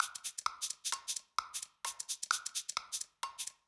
Index of /musicradar/uk-garage-samples/130bpm Lines n Loops/Beats
GA_PercE130-01.wav